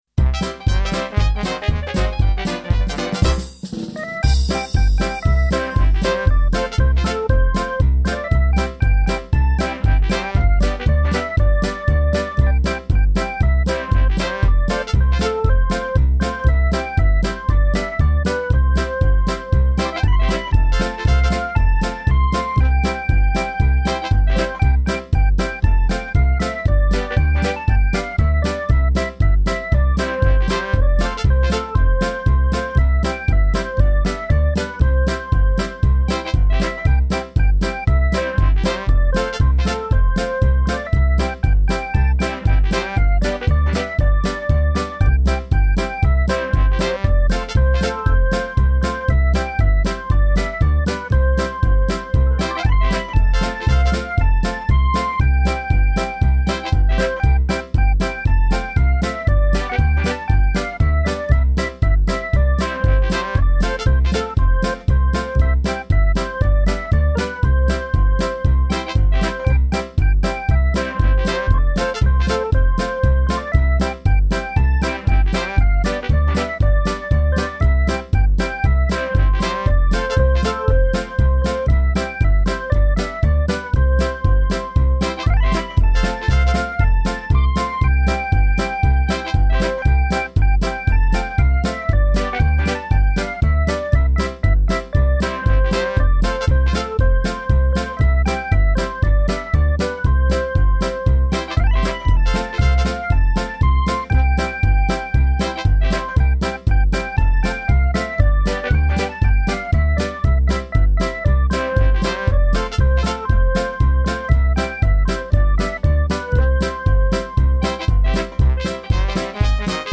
The Brain Song (accompaniment only "Karaoke" format)  MP3 [click to download]
The_Brain_Song_Instrumental_mp3.mp3